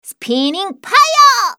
archer_f_voc_skill_spinningfire_speech.mp3